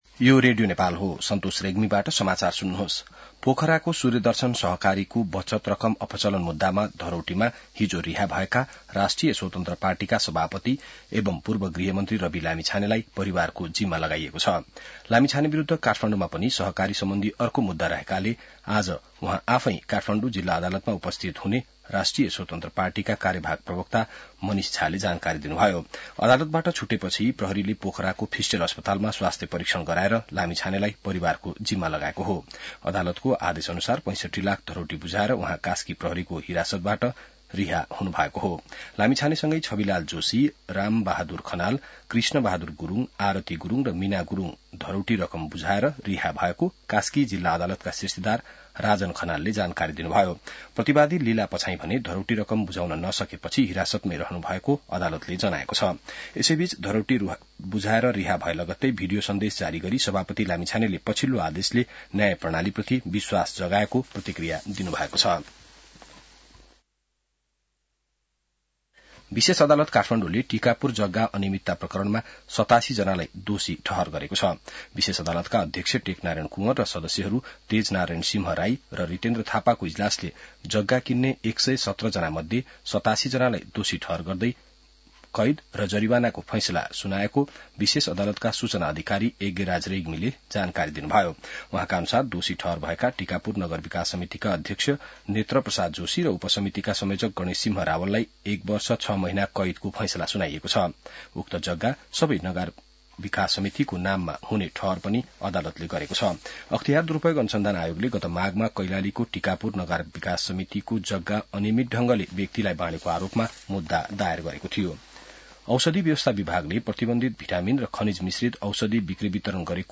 बिहान ६ बजेको नेपाली समाचार : २७ पुष , २०८१